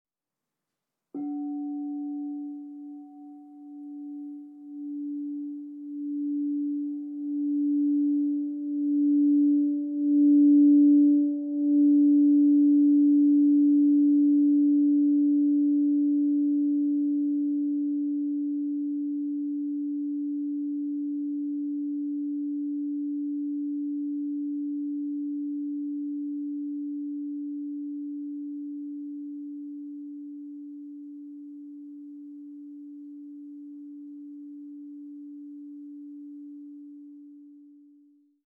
Meinl Sonic Energy 12" Planetary Tuned Crystal Singing Bowl, Mars 144.72 Hz (PCSB12MA)